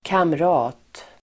Uttal: [kamr'a:t]